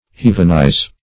Search Result for " heavenize" : The Collaborative International Dictionary of English v.0.48: Heavenize \Heav"en*ize\ (h[e^]v"'n*[imac]z), v. t. To render like heaven or fit for heaven.